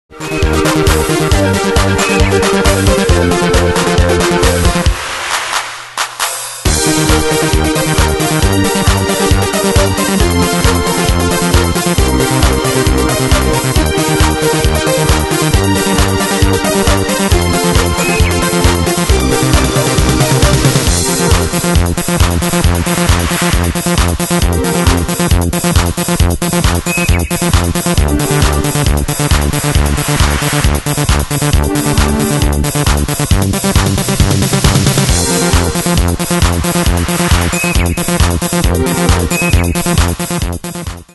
Style: Dance Année/Year: 1996 Tempo: 135 Durée/Time: 3.48
Danse/Dance: Dance Cat Id.
Pro Backing Tracks